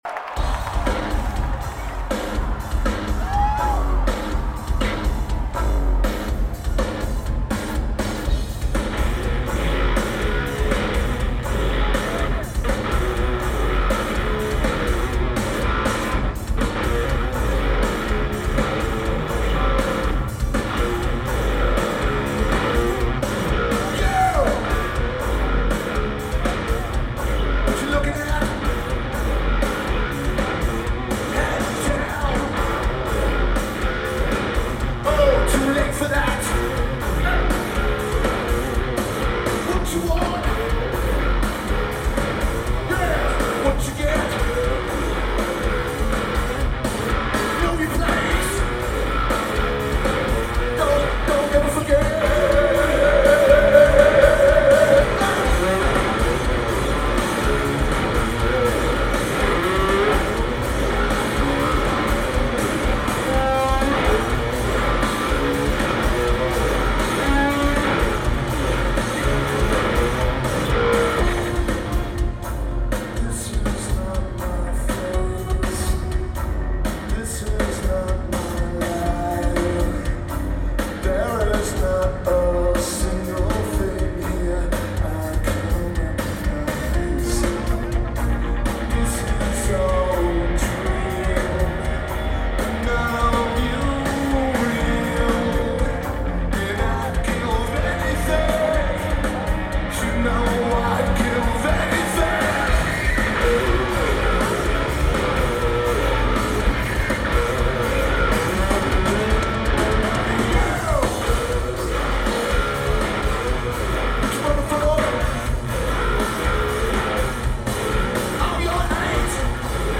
Wachovia Center
Lineage: Audio - AUD (ATu853s + AT8532s + Edirol R09)